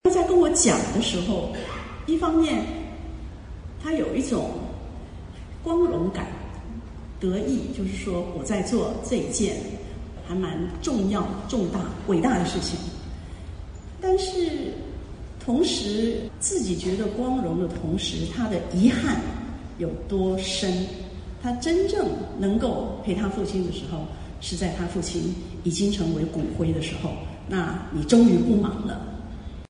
在2019年10月12日于尔湾南海岸中华文化中心举行的读者见面会上，龙应台女士分享对亲情、生死的感悟